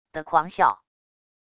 的狂笑wav音频